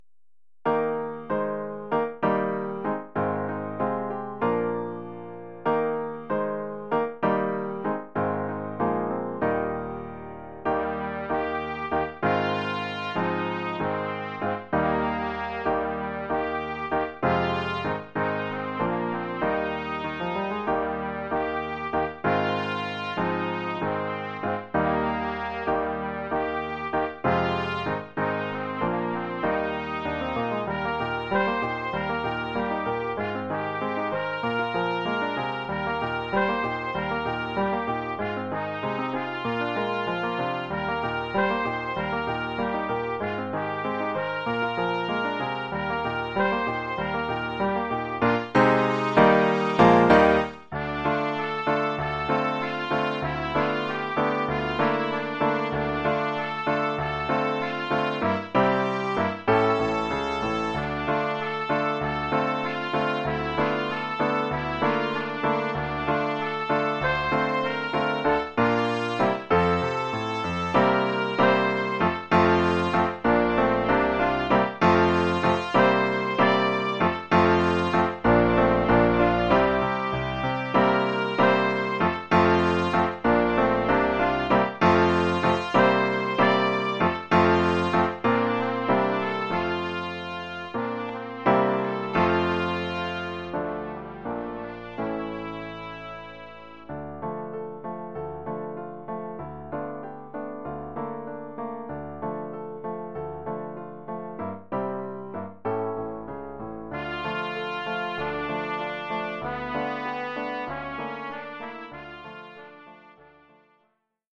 Formule instrumentale : Trompette et piano
Oeuvre pour trompette ou cornet
ou bugle et piano..